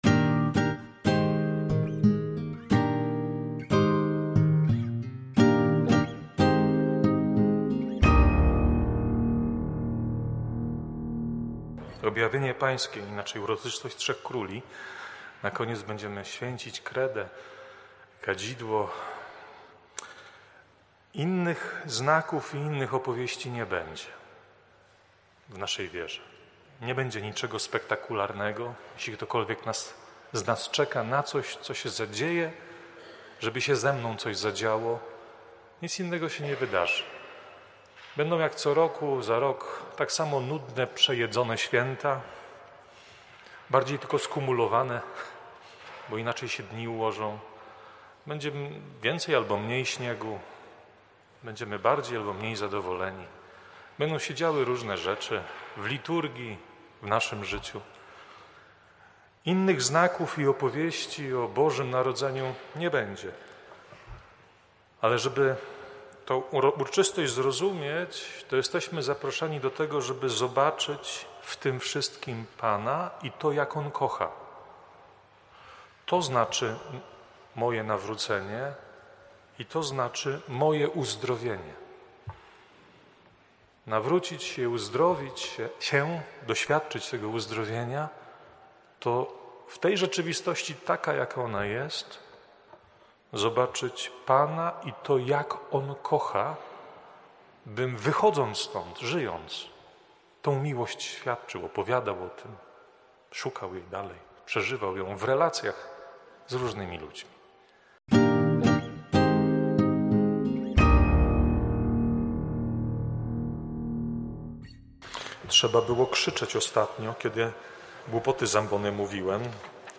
kazania.
wprowadzenie do Liturgii, oraz homilia: